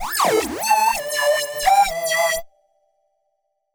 Index of /musicradar/uk-garage-samples/128bpm Lines n Loops/Synths